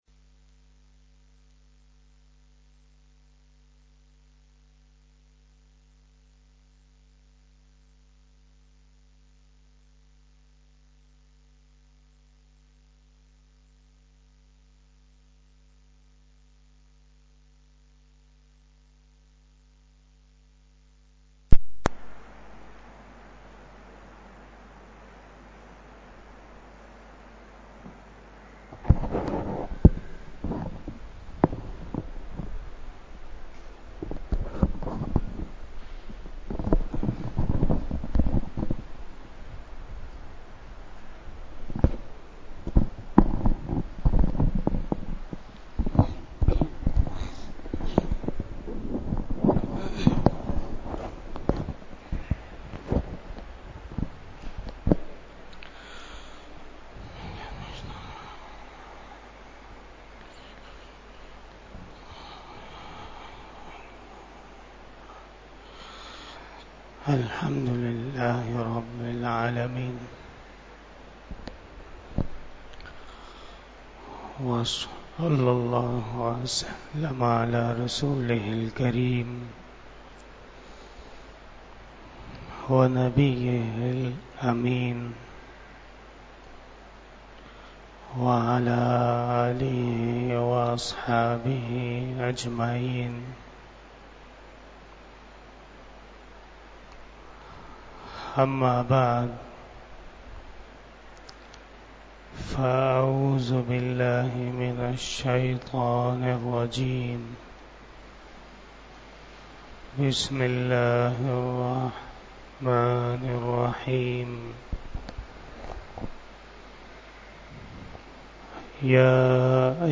بیان جمعۃ المبارک
01:18 PM 307 Khitab-e-Jummah 2023 --